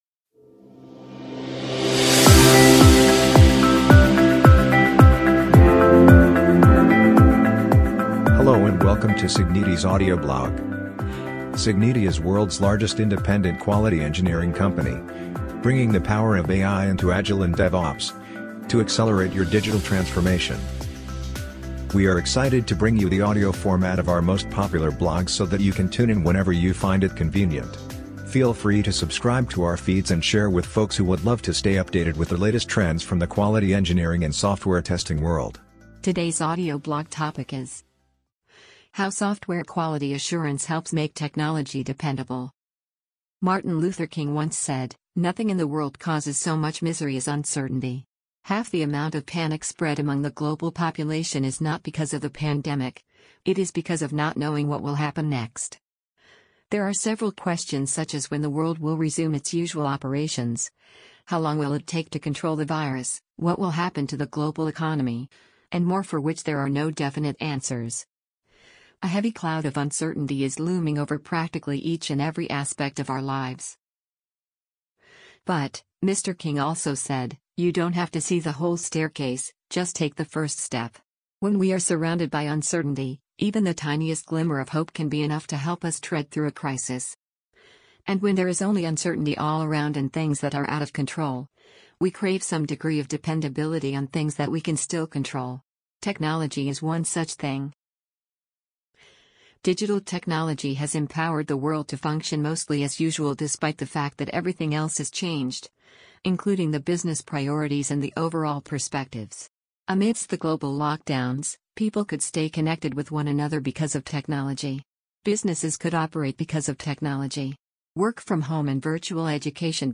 amazon_polly_14277.mp3